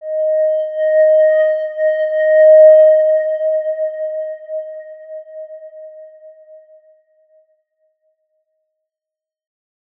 X_Windwistle-D#4-pp.wav